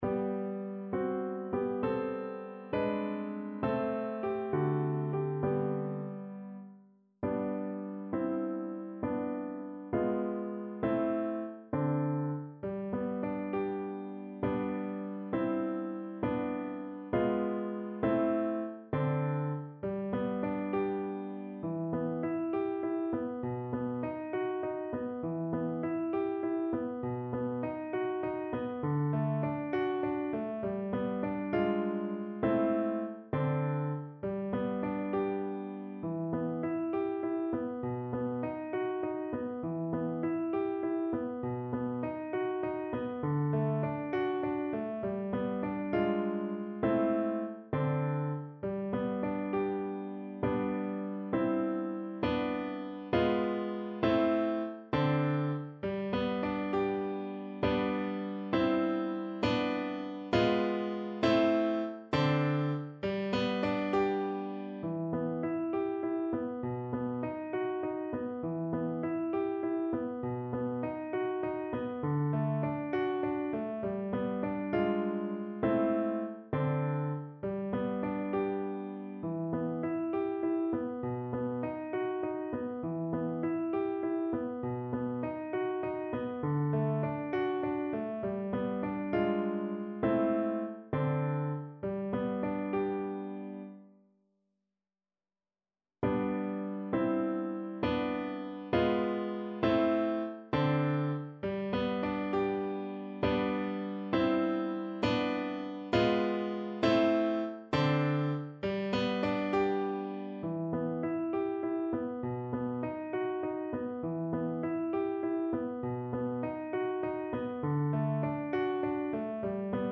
kolęda: O gwiazdo betlejemska (na flet i fortepian)
Symulacja akompaniamentu